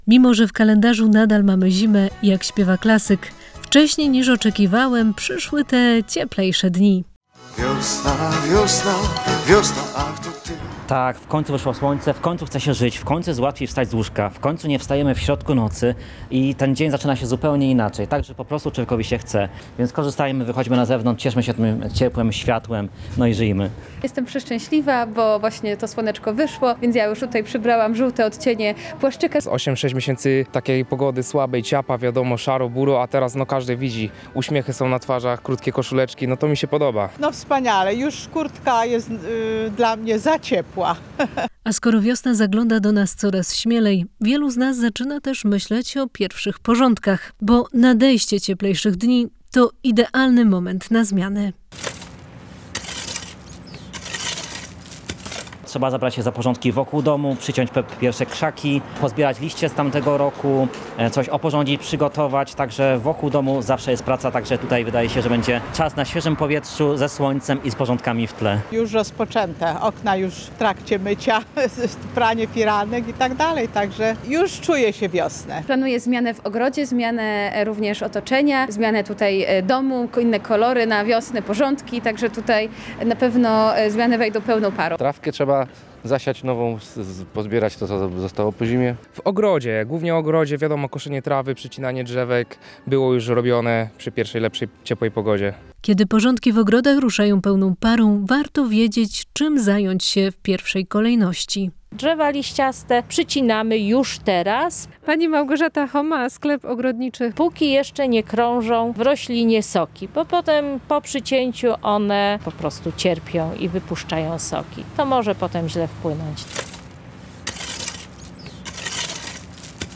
Mieszkańcy czują zmianę pogody [SONDA]